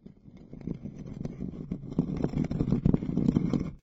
Ambient2.ogg